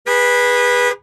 honk3.ogg